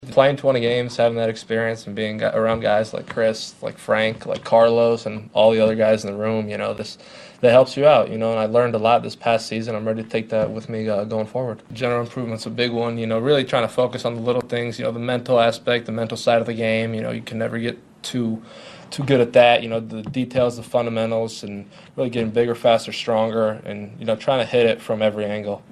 Defensive End George Karlaftis met with the media yesterday, he said he learned a lot last year and is looking to improve.